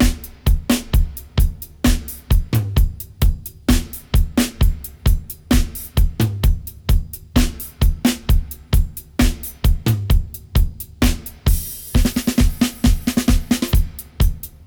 129-FX-05.wav